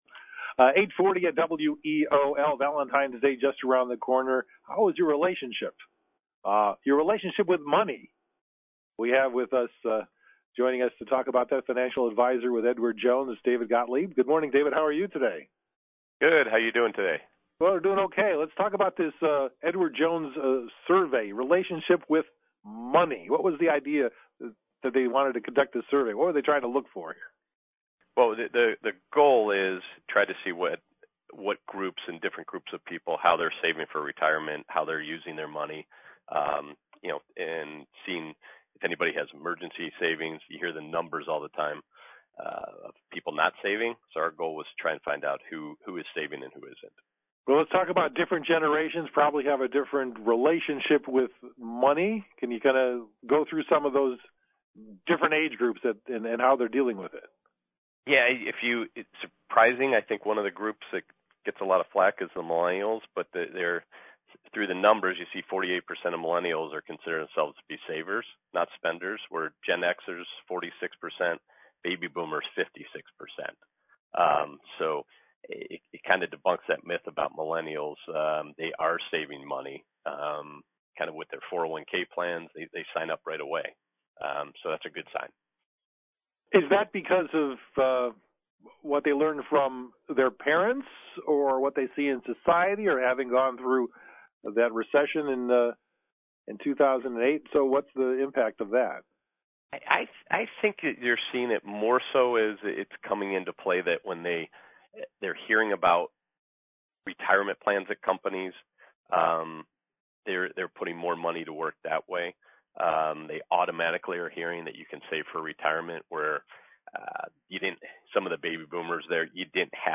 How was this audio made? ten minute taped interview on WEOL-AM